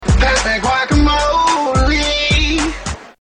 autotune